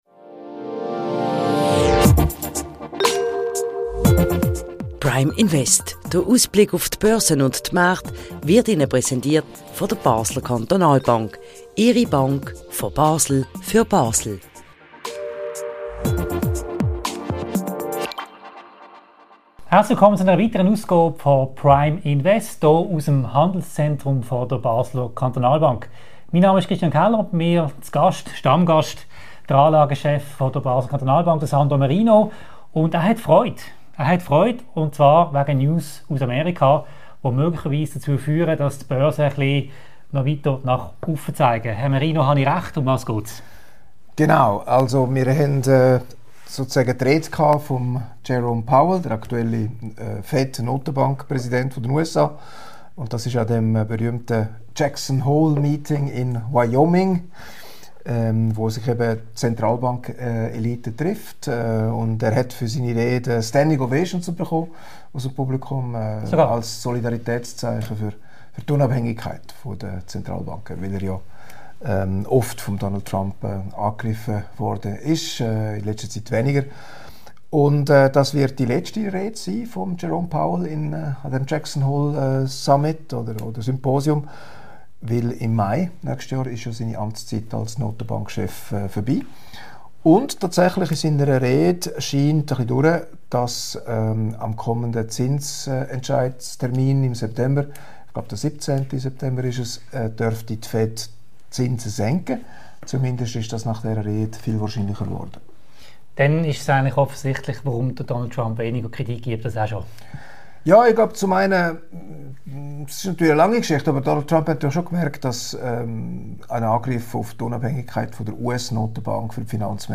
Im Videogespräch